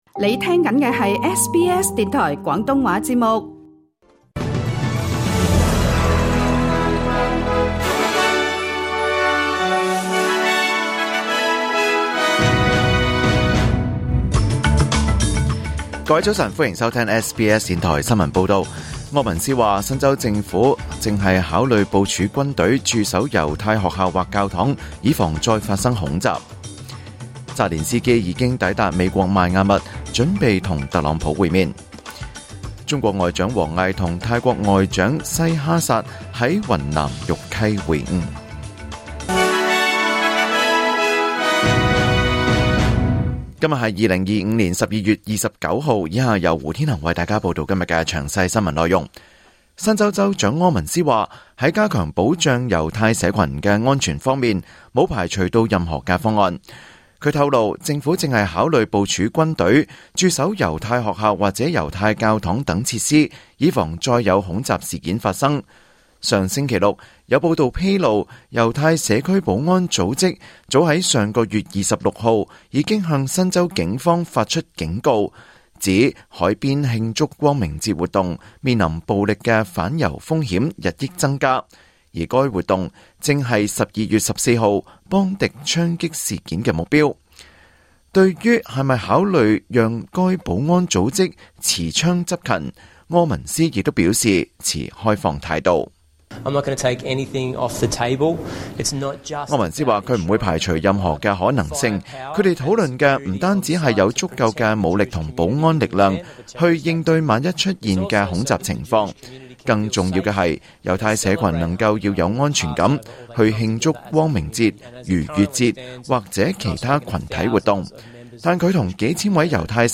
2025年12月29日SBS廣東話節目九點半新聞報道。